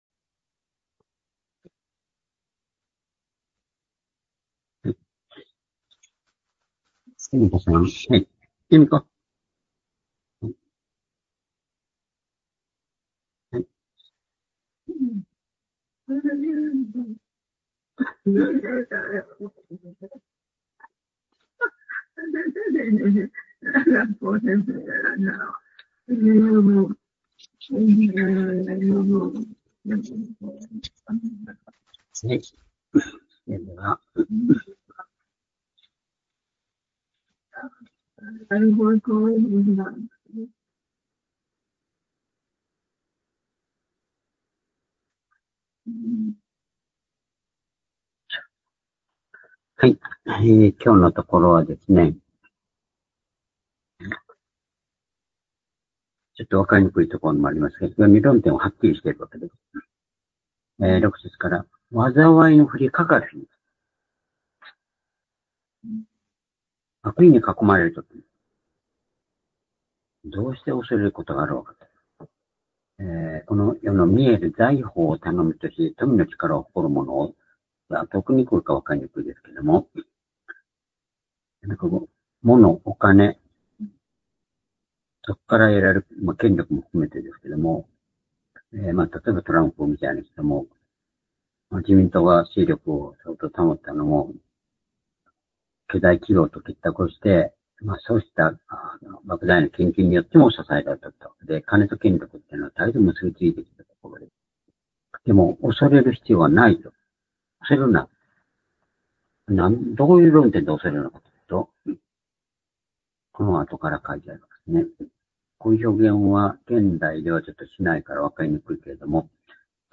「滅びのなかから救いだす力」-詩編49編6節～21節-２０２５年５月６日（夕拝)